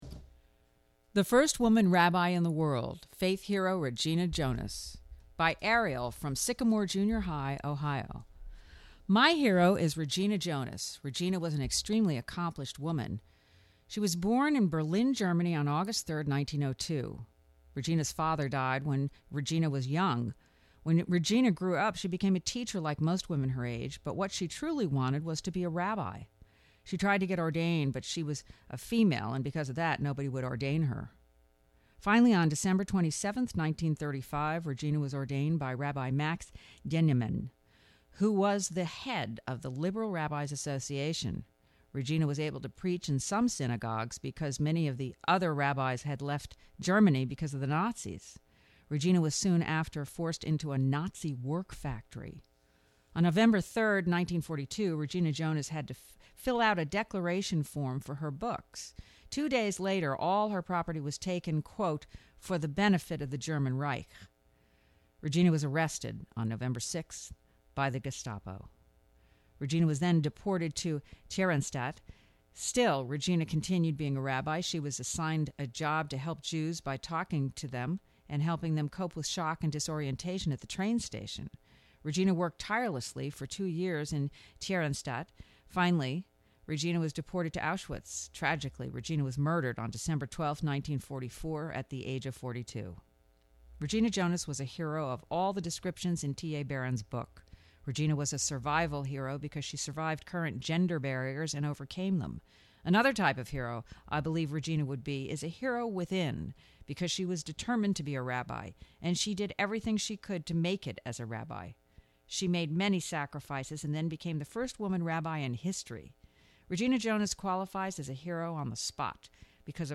Please enjoy this reading of our MY HERO story